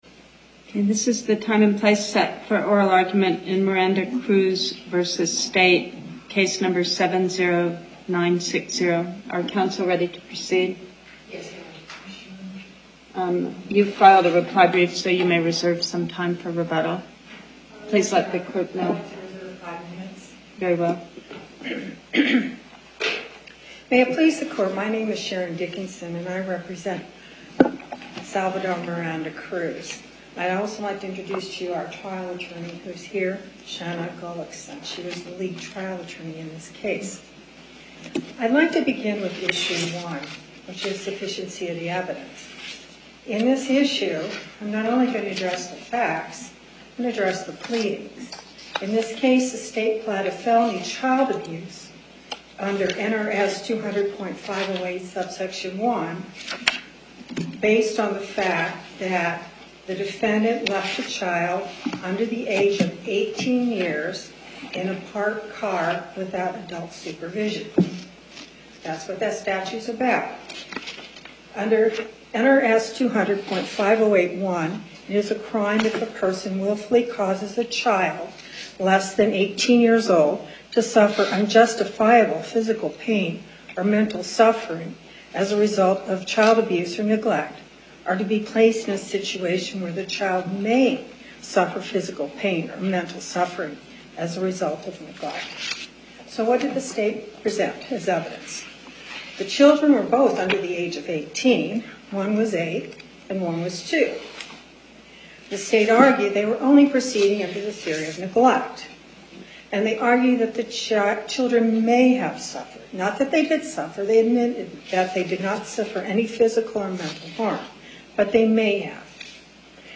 2:00 P.M. Location: Las Vegas Before the Northern Nevada Panel, Justice Pickering Presiding Appearances